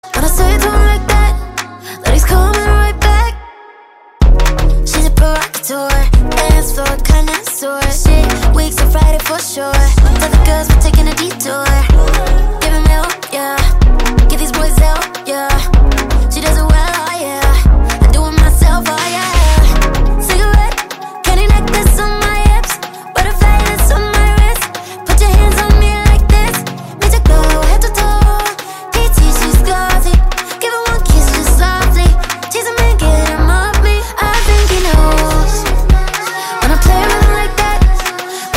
Catégorie POP